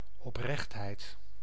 Ääntäminen
Synonyymit openhartigheid trouwhartigheid rondborstigheid Ääntäminen Haettu sana löytyi näillä lähdekielillä: hollanti Käännös Ääninäyte Substantiivit 1. sincerity 2. truth US 3. probity Suku: f .